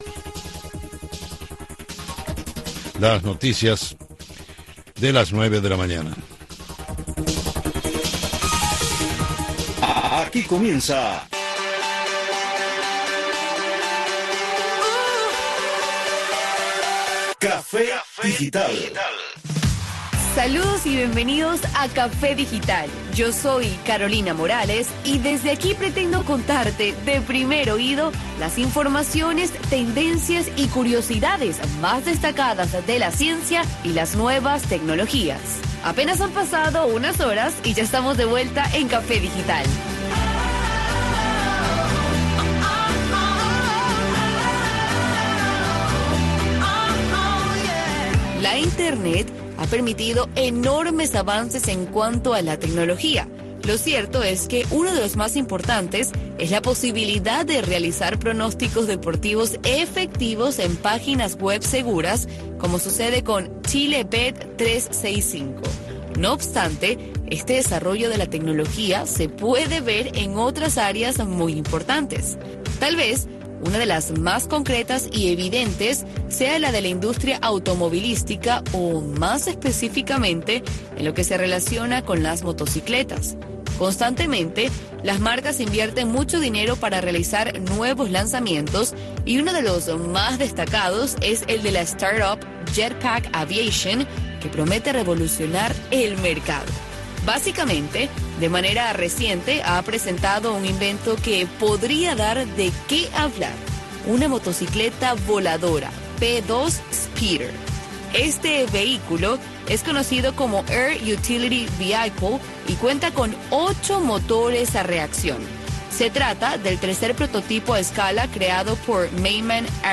Un espacio radial que va más allá de los nuevos avances de la ciencia y la tecnología, pensado para los jóvenes dentro de la isla que emplean las nuevas tecnologías para dar solución a sus necesidades cotidianas.